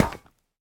Minecraft Version Minecraft Version 1.21.5 Latest Release | Latest Snapshot 1.21.5 / assets / minecraft / sounds / block / netherite / step1.ogg Compare With Compare With Latest Release | Latest Snapshot
step1.ogg